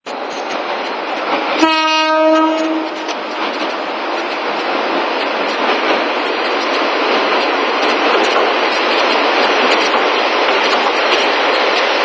余部鉄橋～餘部駅　ｷﾊ47 (2両) 12秒